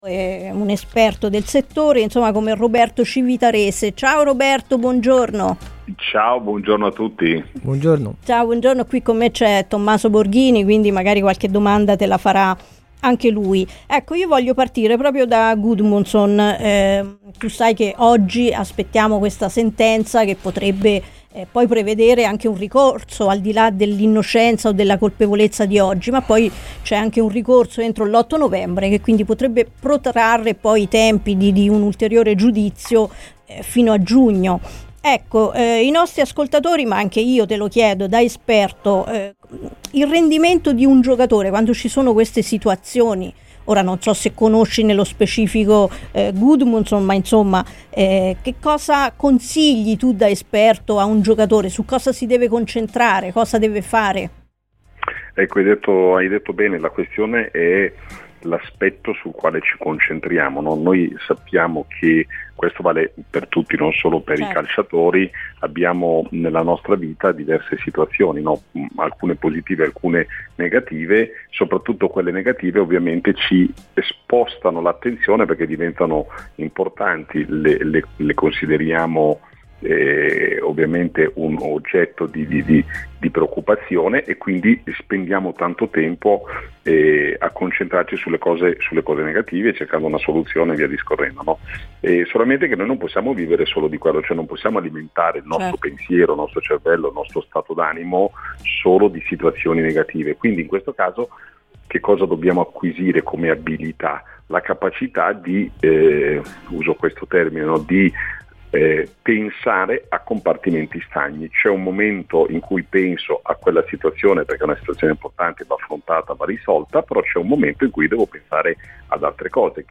Il mental coach